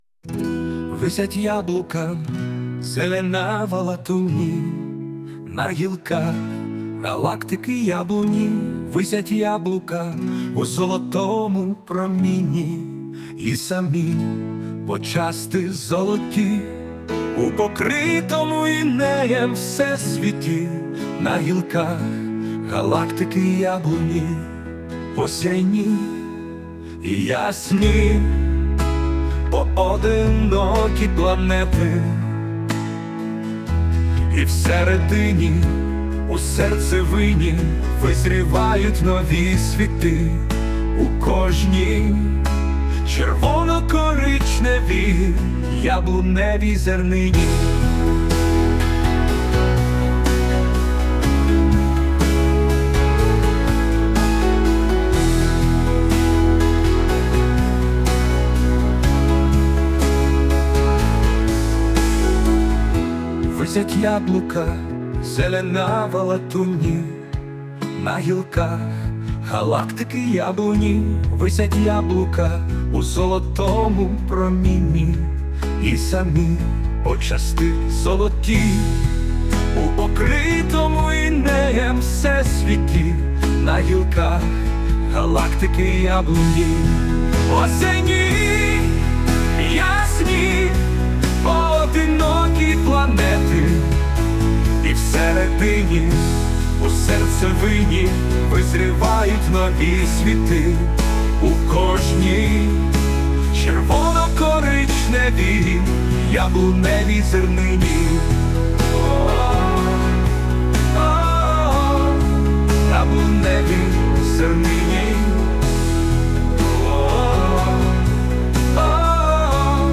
на вірш "Яблука" ШІ згенеровано музику і виконання